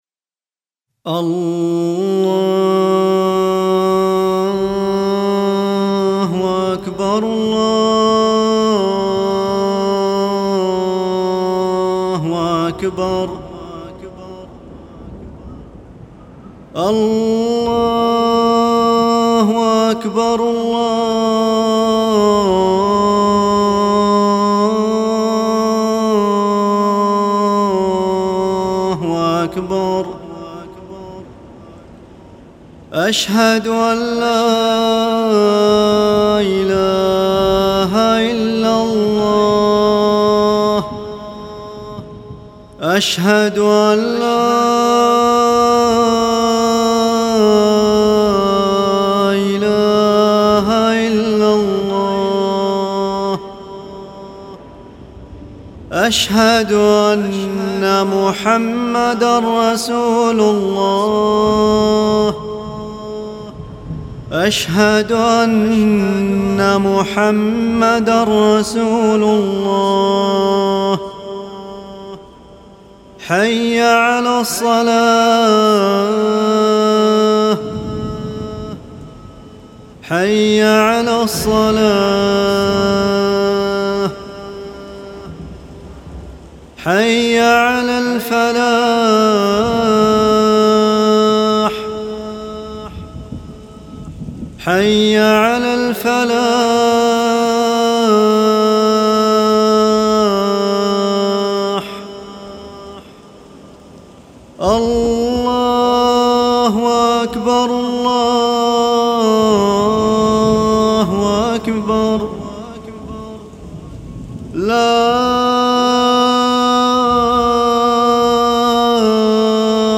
آذان